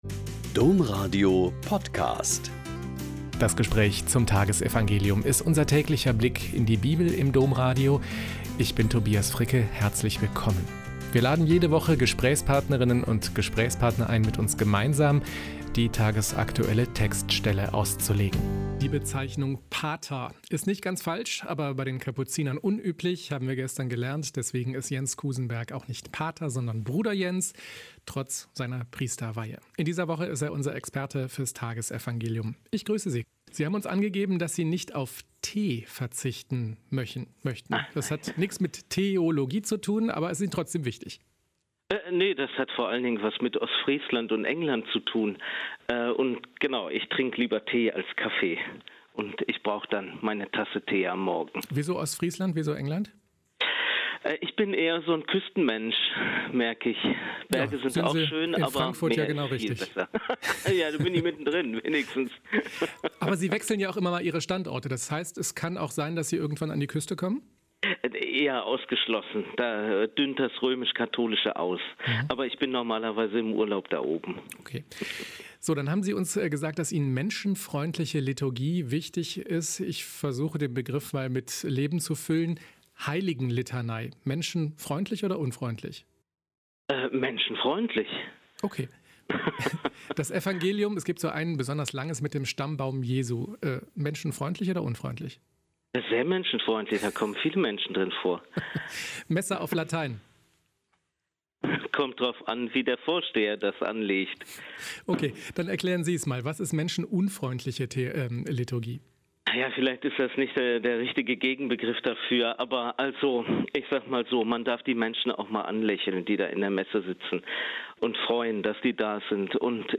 Lk 11,1-4 - Gespräch